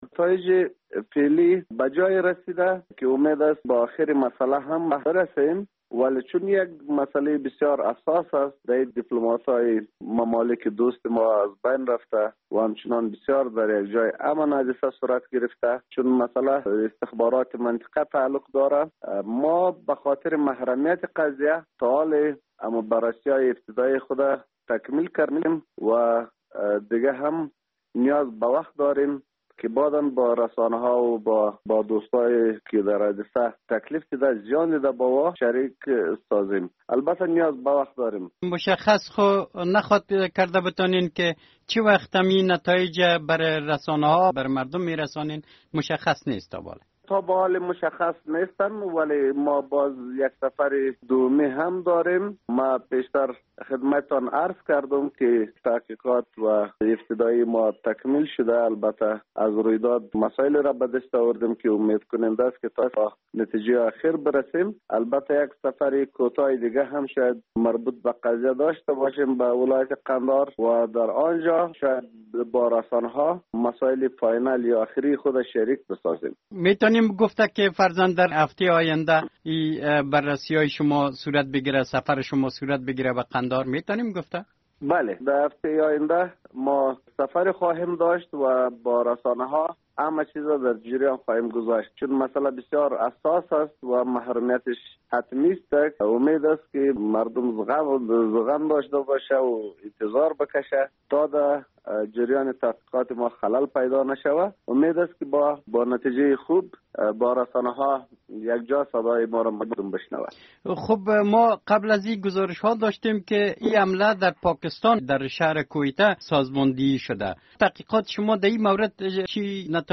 مصاحبه - صدا
محمد داود گلزار مشاور ارشد رئیس جمهور غنی در امور سیاسی، هئیت ده نفری فرستاده شده از سوی ریاست جمهوری را رهبری میکند.